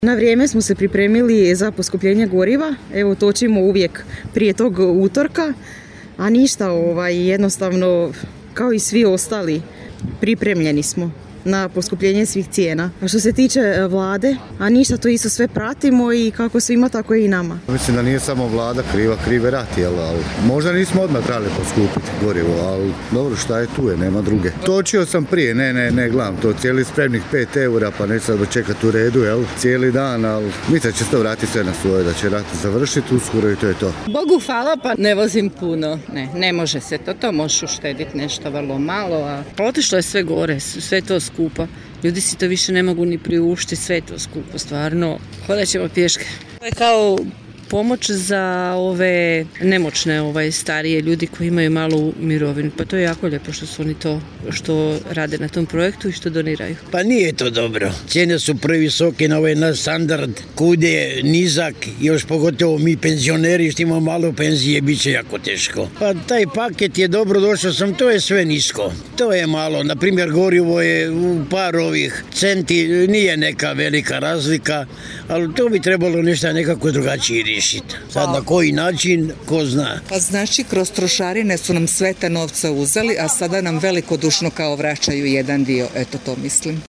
Anketa: što sugrađani misle o poskupljenju goriva
Naše smo sugrađane pitali što misle o poskupljenju goriva, jesu li možda na benzinske crpke odlazili u danima prije najavljenog poskupljenja te što misle o Vladinom desetom paketu mjera pomoći: